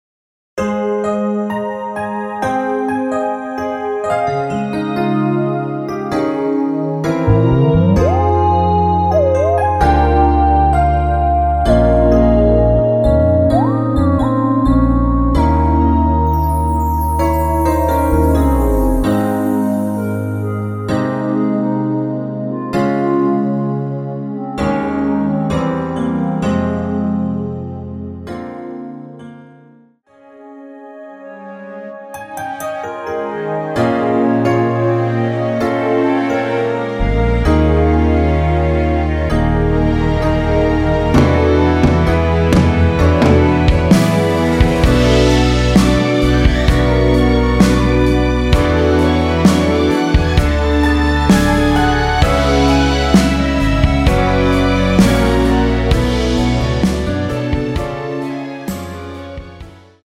대부분의 남성분이 부르실수 있는 키로 제작 하였습니다.
원키에서(-9)내린 멜로디 포함된 MR입니다.
Ab
앞부분30초, 뒷부분30초씩 편집해서 올려 드리고 있습니다.
중간에 음이 끈어지고 다시 나오는 이유는